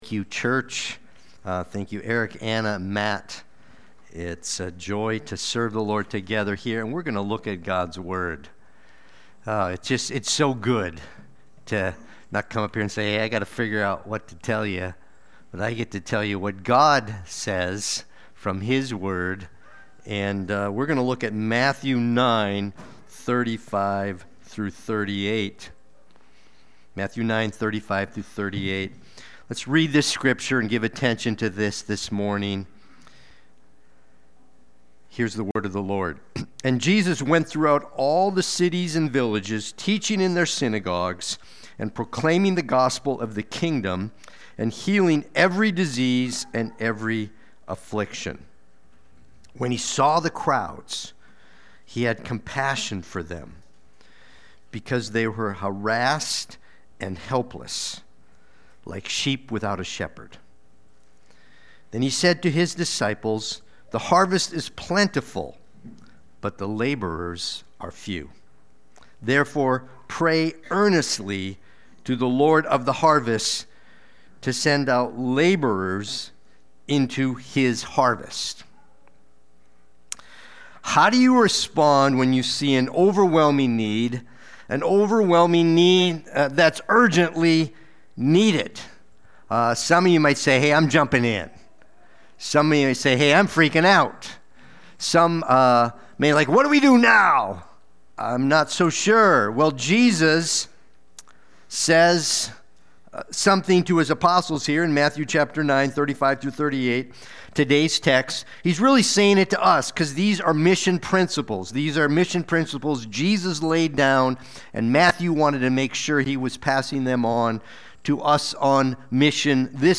Watch the replay or listen to the sermon.
Sunday-Worship-main-42725.mp3